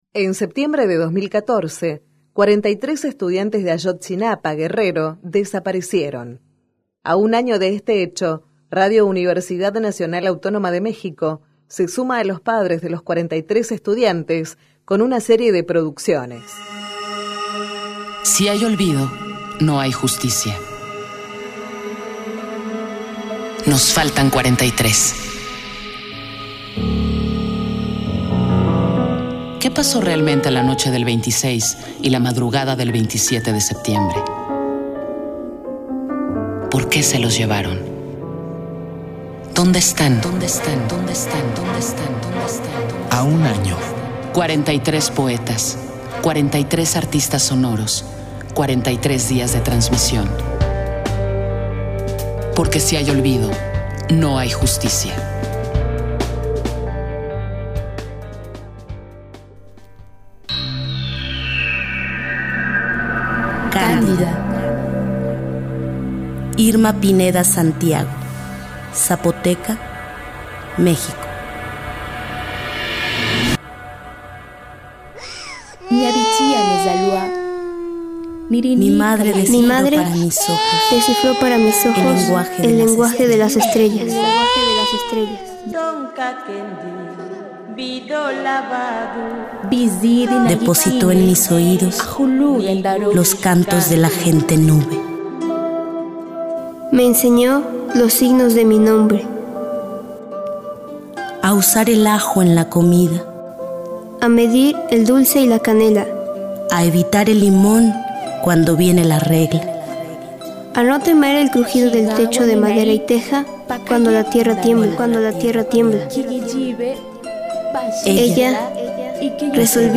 A un año de los sucesos, la producción de Radio UNAM reúne distintas personalidades del ámbito radiofónico que, entre poesía, música y ambientes sonoros, rescatan la memoria viva del sonado caso de los estudiantes desaparecidos de la Escuela Normal Rural de Ayotzinapa.